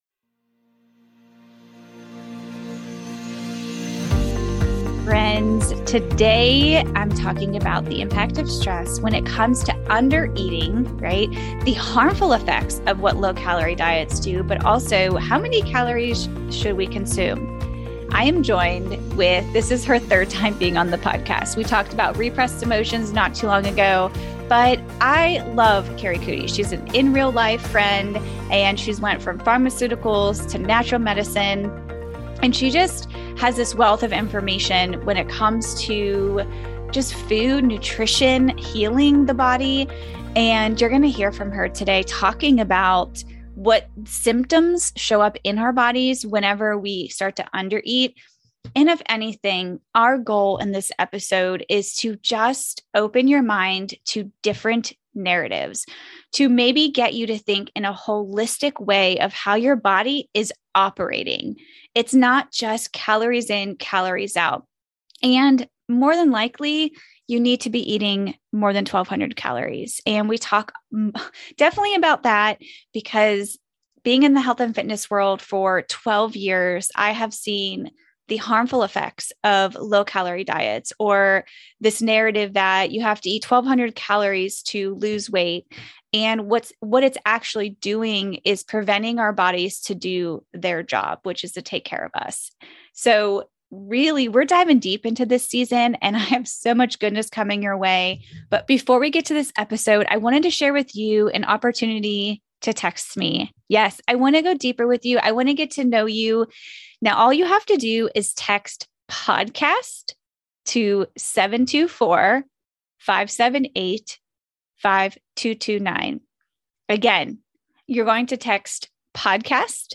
Headliner Embed Embed code See more options Share Facebook X Subscribe I'm excited to share with you this conversation with Integrative Health Pharmacist